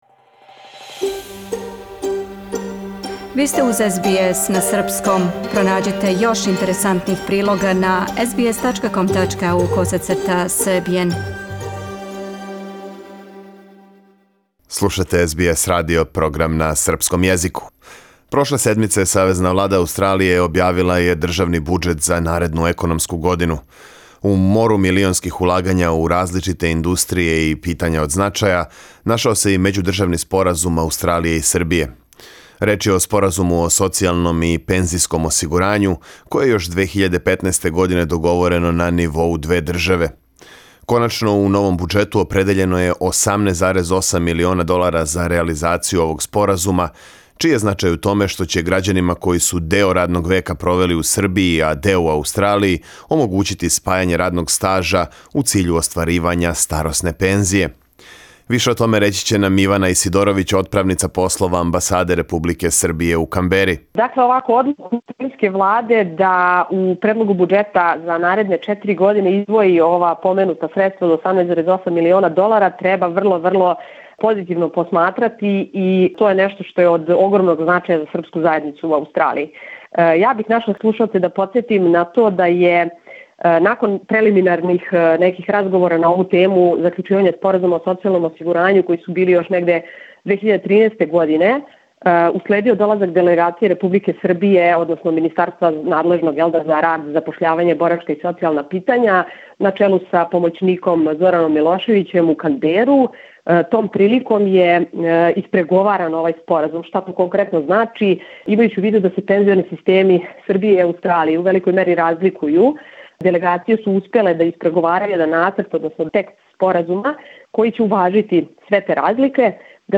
О реализацији Споразума разговарали смо с Иваном Исидоровић, отправницом послова Амбасаде Републике Србије у Канбери.